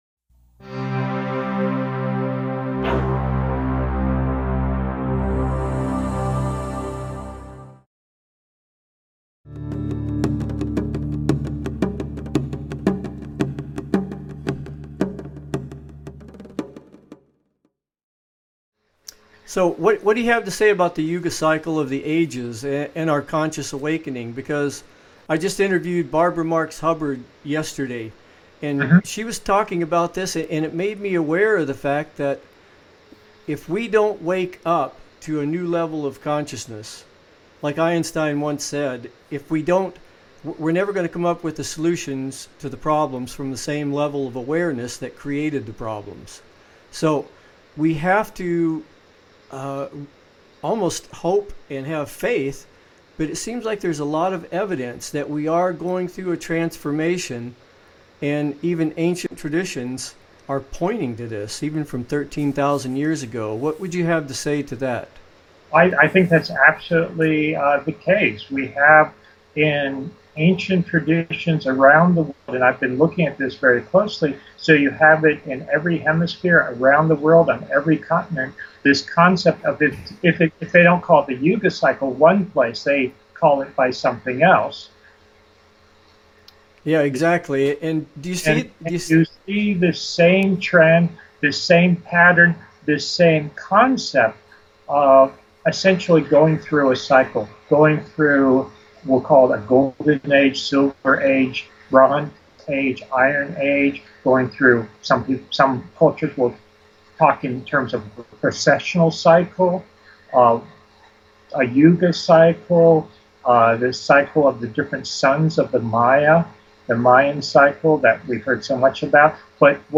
Guest, Robert Shoch on Ancient Civilizations